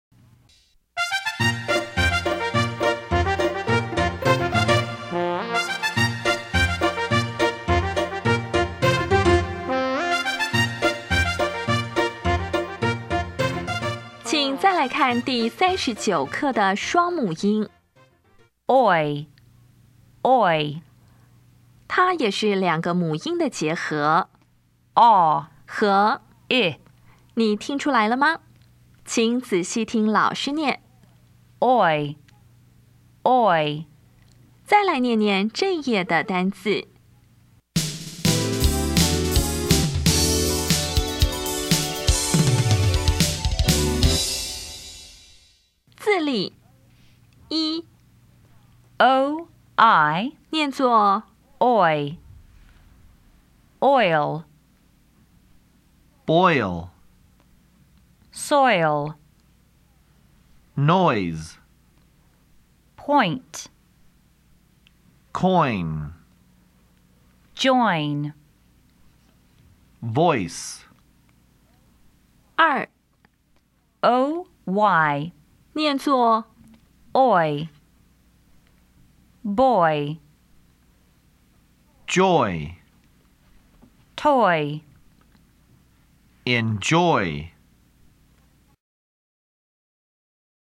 [ɔɪ]
音标讲解第三十九课
[ɔɪl]
[sɔɪl]
[pɔɪnt]
[dʒɔɪn]
[bɔɪl]
[nɔɪz]
[kɔɪn]
[vɔɪs]
[bɔɪ]
[tɔɪ]
[dʒɔɪ]
[ɪnˋdʒɔɪ]